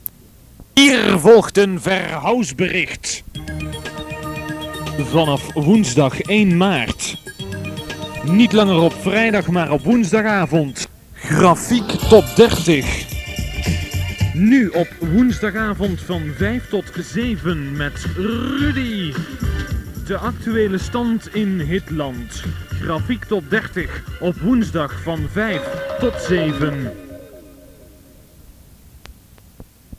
Reclamespot Top 30 verhuisd naar woensdagavond.mp3